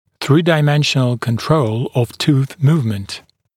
[ˌθriːdaɪ’menʃənl kən’trəul əv tuːθ ‘muːvmənt][ˌсри:дай’мэншэнл кэн’троул ов ту:с ‘му:вмэнт]трёхмерный контроль перемещения зуба, контроль за перемещением зуба в трёх плоскостях